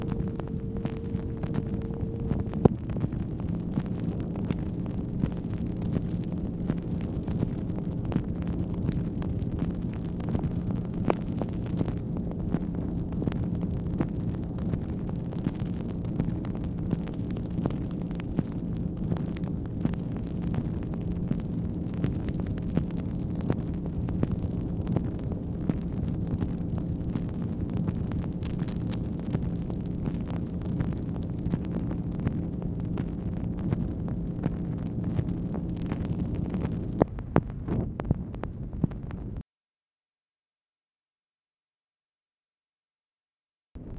MACHINE NOISE, August 3, 1964
Secret White House Tapes | Lyndon B. Johnson Presidency